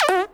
cartoon_boing_jump_06.wav